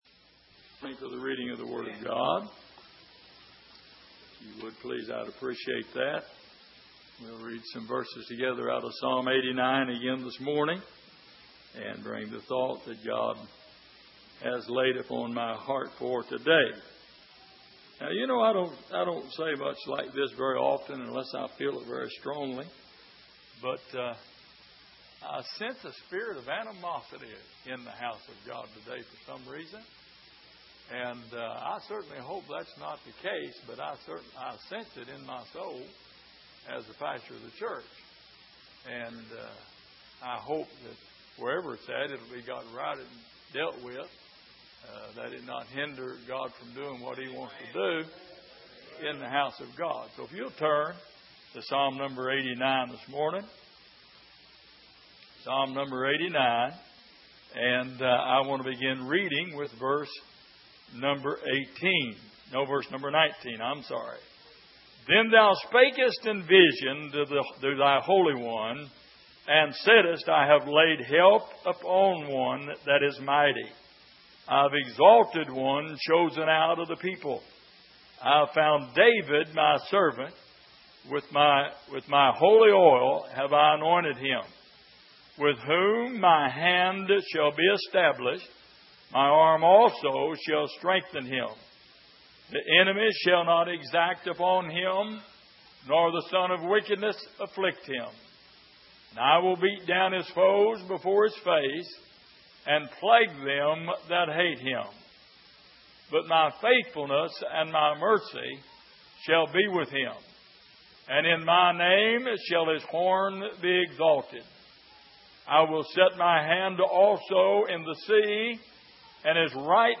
Passage: Psalm 89:18-37 Service: Sunday Morning